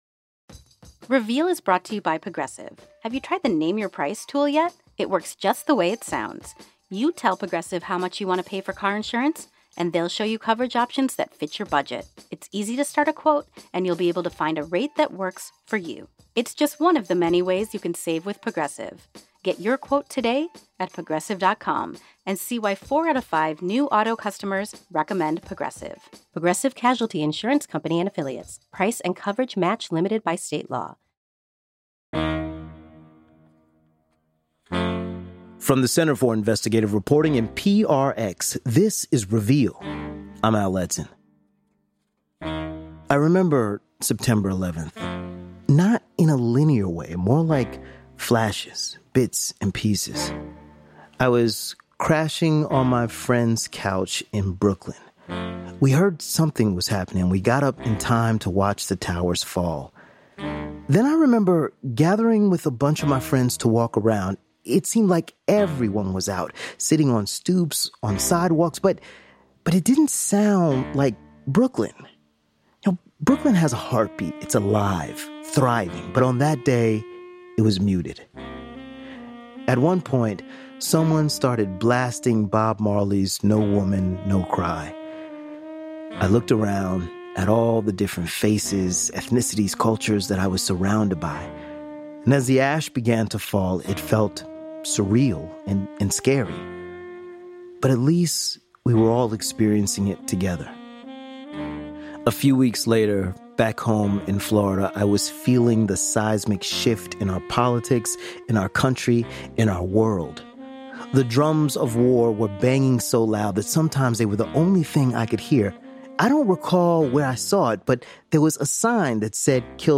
On 9/11, the U.S. swore to “never forget.” But who gets remembered? We hear from reporters on Afghanistan, Iraq and Yemen, where the aftermath of 9/11 is acutely felt two decades later.